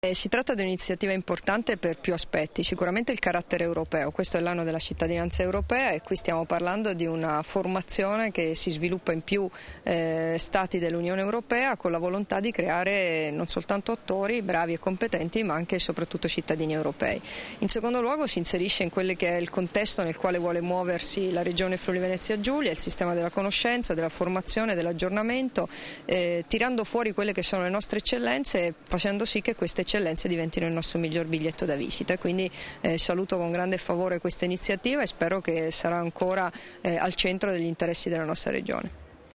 Dichiarazioni di Debora Serracchiani (Formato MP3) [630KB]
rilasciate a margine dell'incontro con Constanza Macras e i partner della XXII edizione de "L'Ecole des Maîtres. Corso internazionale itinerante di perfezionamento teatrale", a Udine il 2 settembre 2013